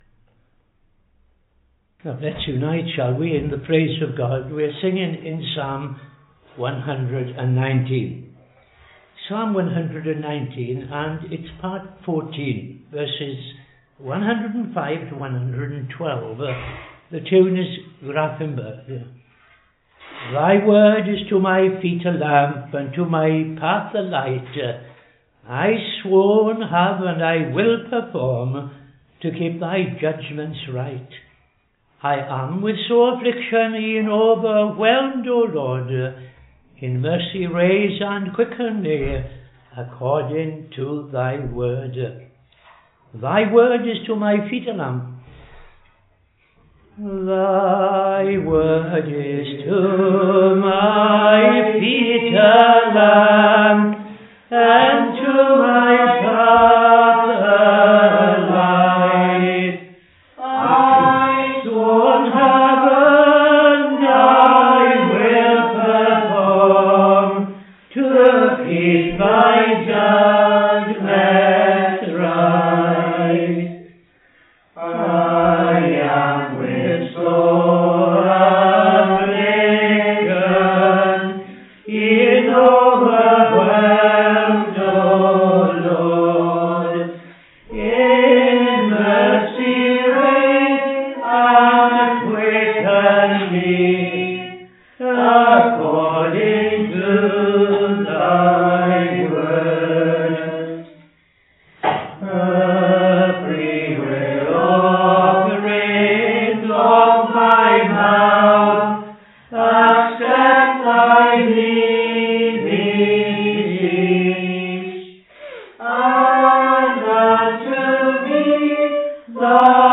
Evening Service - TFCChurch
5.00 pm Evening Service Opening Prayer and O.T. Reading I Chronicles 12:22-40